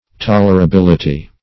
tolerability - definition of tolerability - synonyms, pronunciation, spelling from Free Dictionary
Search Result for " tolerability" : The Collaborative International Dictionary of English v.0.48: Tolerability \Tol`er*a*bil"i*ty\, n. The quality or state of being tolerable.